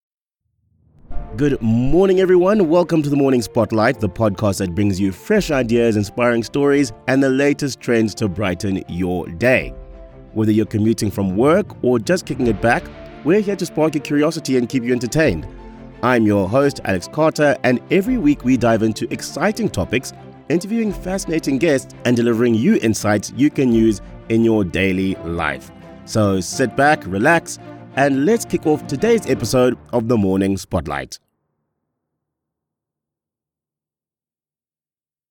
English (South African)
-A warm, dynamic, and professional voice
-High-quality recordings from my state-of-the-art home studio
Audio Technica AT2020 Cardioid Condenser Microphone